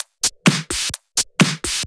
Index of /VEE/VEE Electro Loops 128 BPM
VEE Electro Loop 041.wav